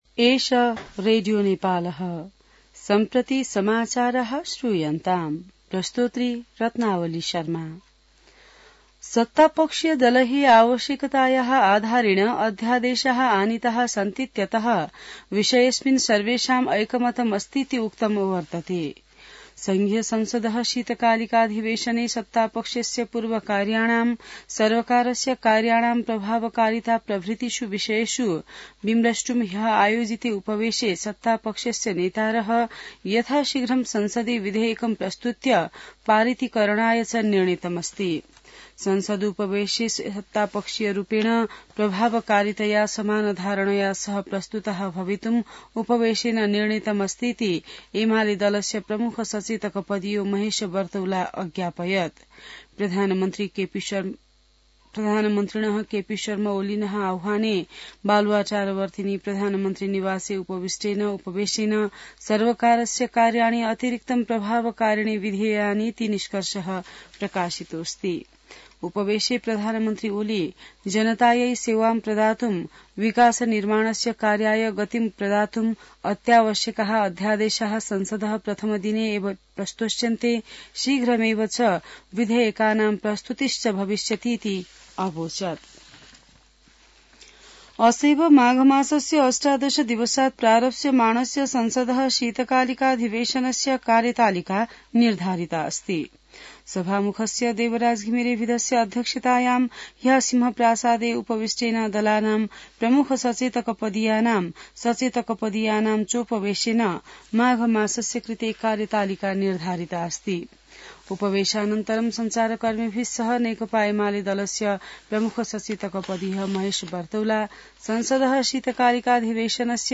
संस्कृत समाचार : १६ माघ , २०८१